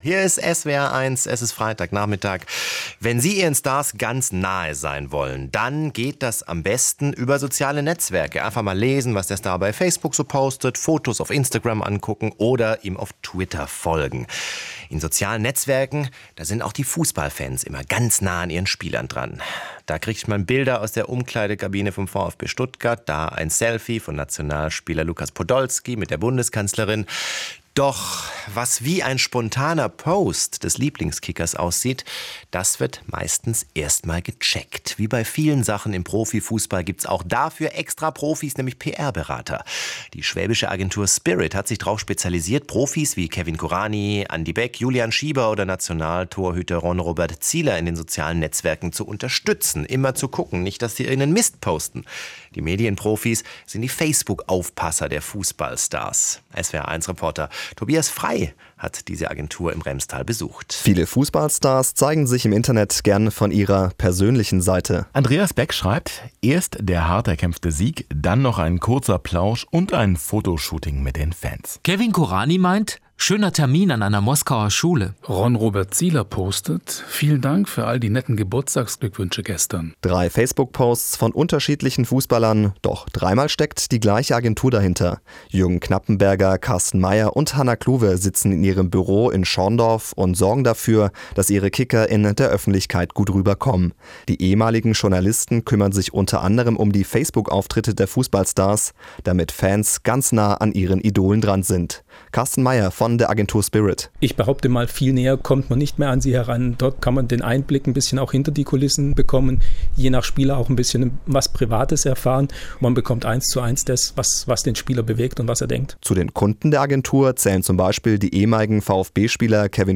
Medium: SWR1 Hörfunk
> Foto vom Interview